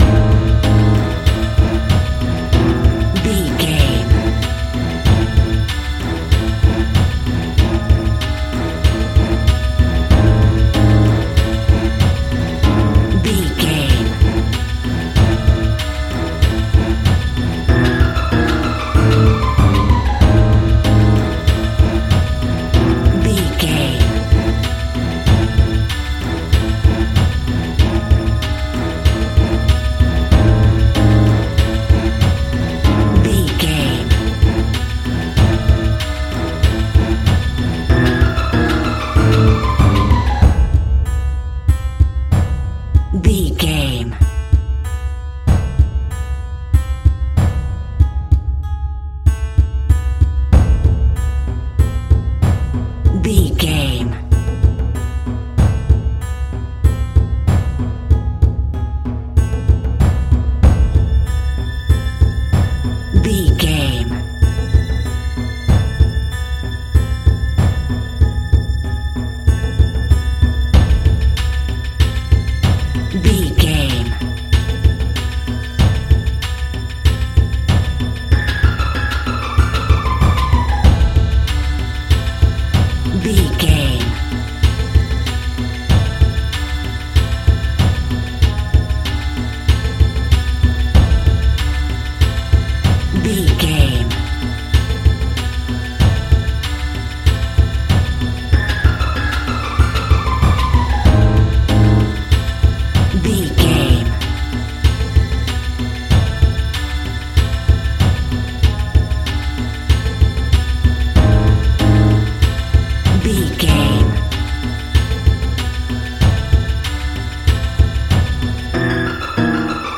Aeolian/Minor
middle east music
ethnic percussion
ethnic strings